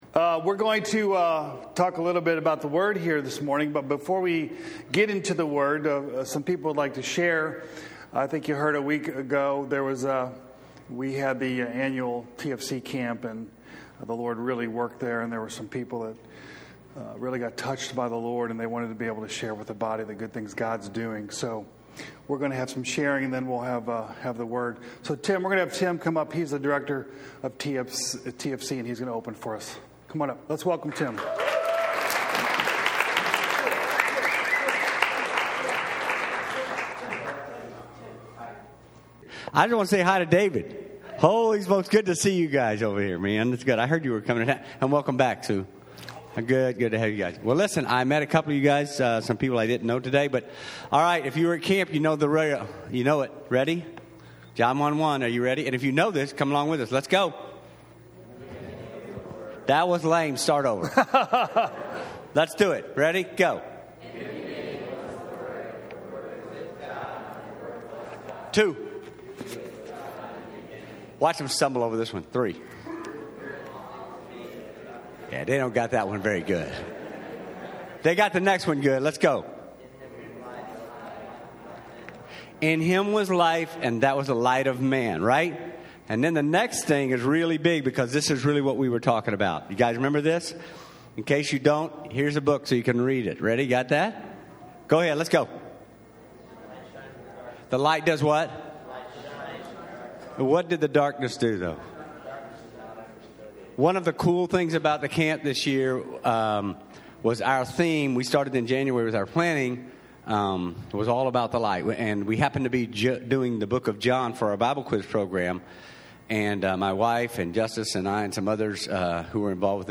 TFC Camp Sharing; Exhortation on 2 John 8